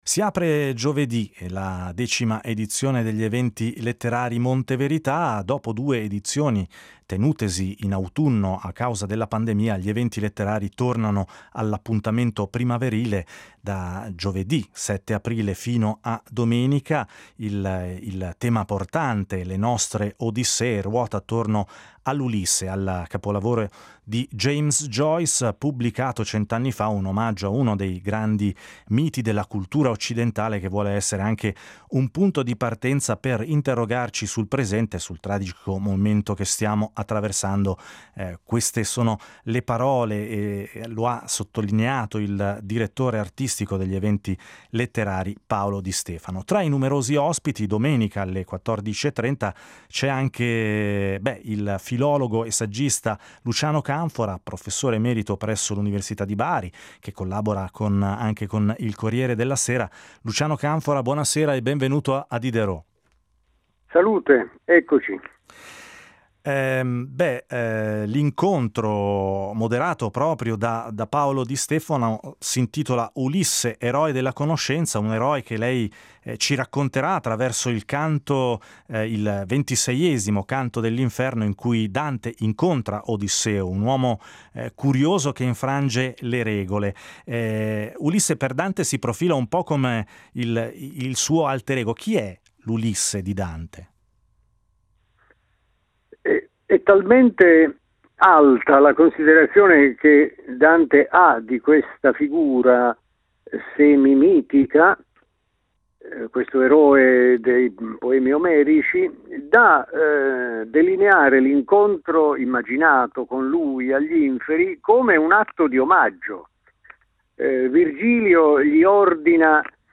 l’incontro con Luciano Canfora , uno tra gli ospiti della decima edizione degli Eventi letterari Monte Verità .